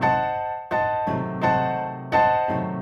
Index of /musicradar/gangster-sting-samples/85bpm Loops
GS_Piano_85-A1.wav